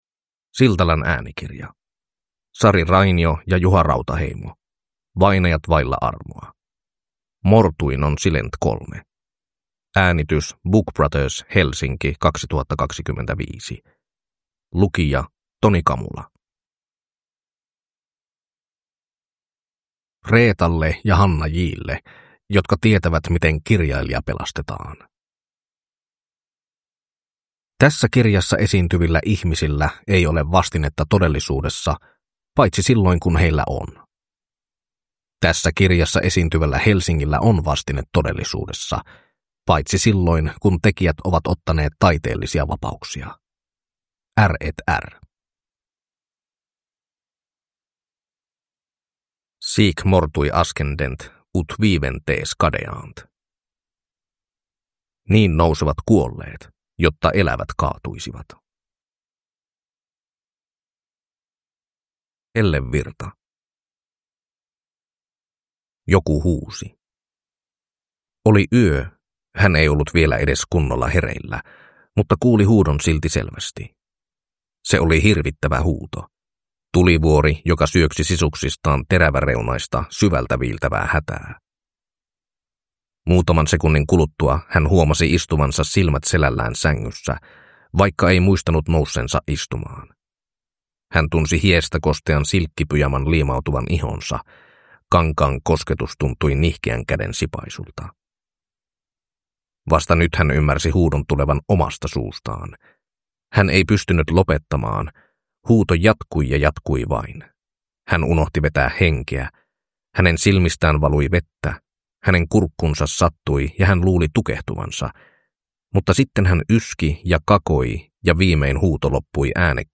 Vainajat vailla armoa (ljudbok) av Juha Rautaheimo